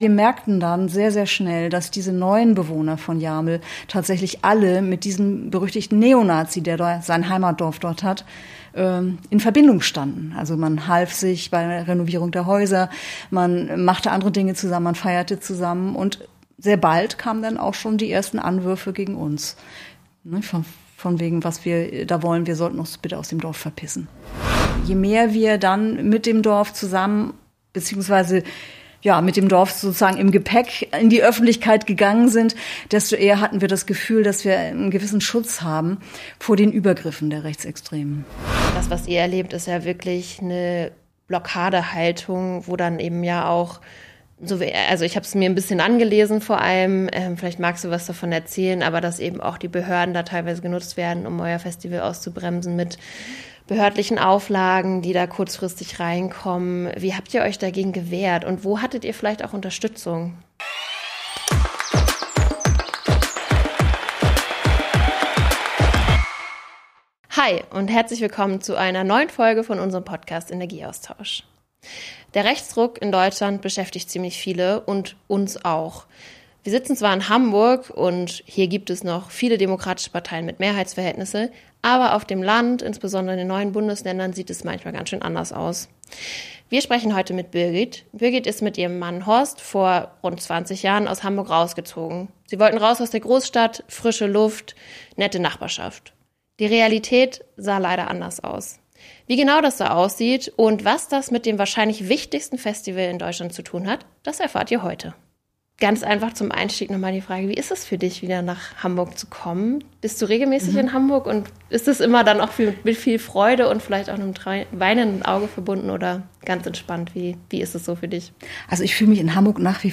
Das Interview wurde am 19.11.2025 aufgenommen.